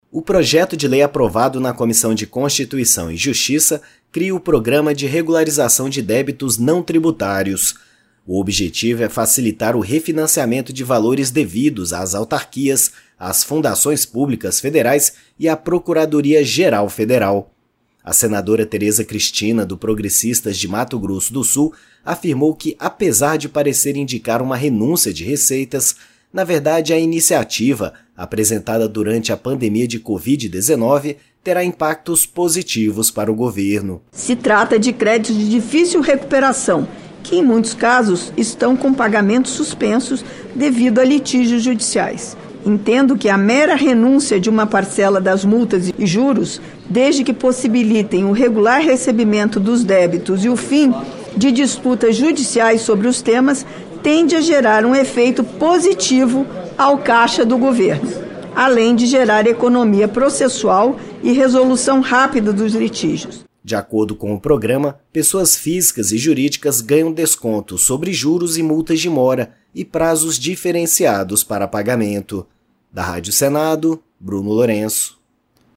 A senadora Teresa Cristina (PP-MS) diz que, apesar de indicar renúncia de receitas, a iniciativa, na verdade, terá impactos positivos para o governo federal, pois trata de créditos de difícil recuperação.